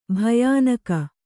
♪ bhayānaka